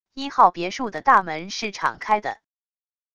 一号别墅的大门是敞开的wav音频生成系统WAV Audio Player